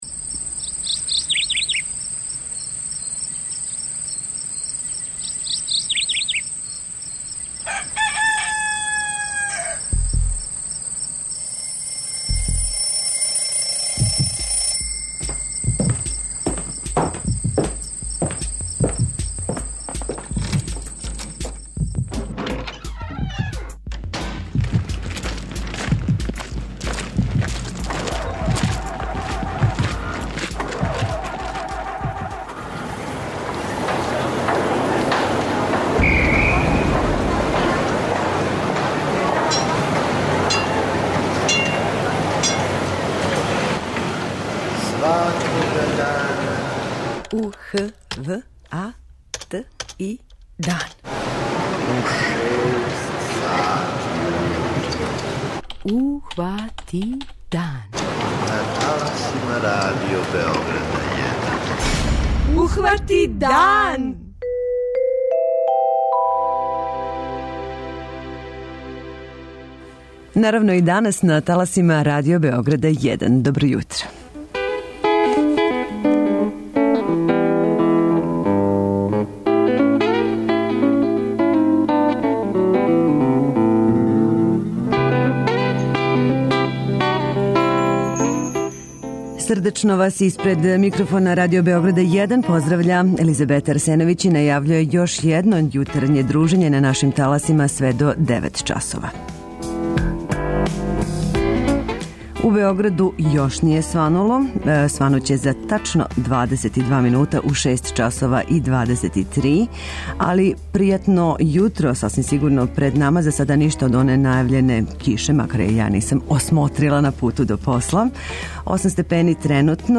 У Јутарњем програму ове среде о: